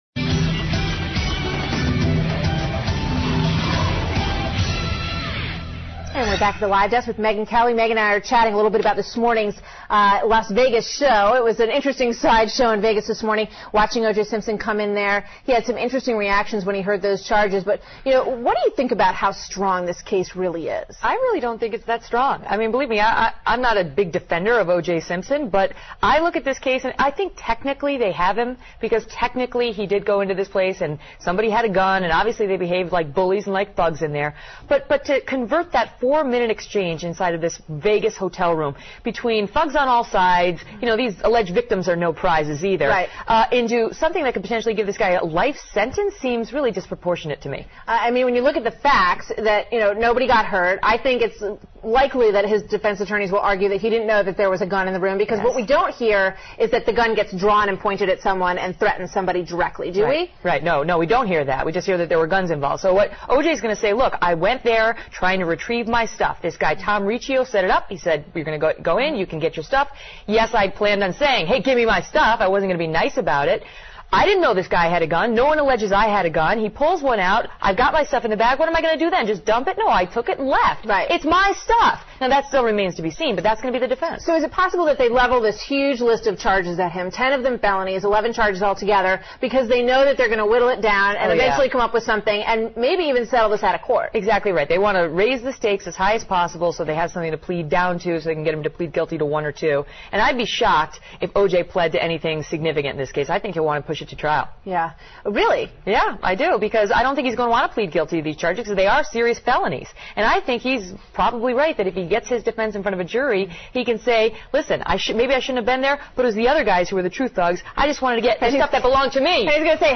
访谈录 【Interview】2007-09-24&09-25, Simpson还能走多远 听力文件下载—在线英语听力室